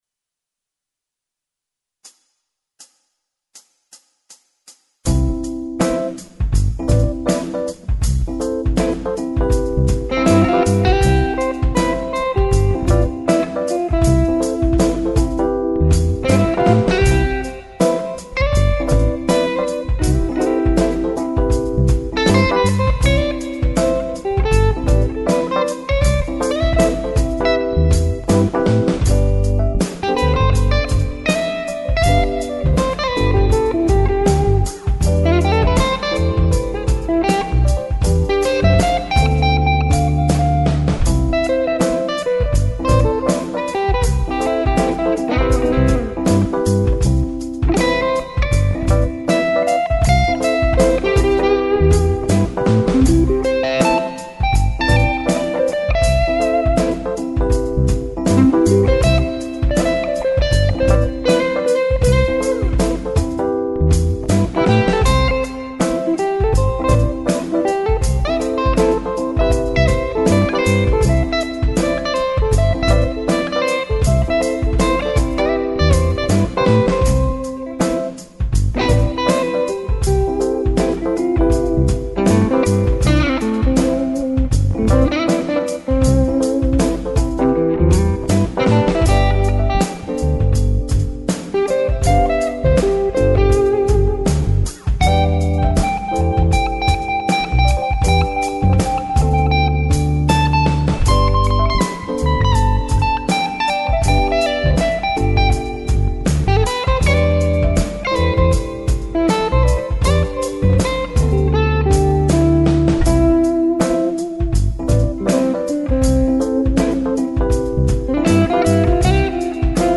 so jetzt doch noch ein Versuch "jazzig" zu klingen.
Naja - der Sound und auch die Attitude ist nicht so richtig jazzig - aber halt einen Versuch Wert.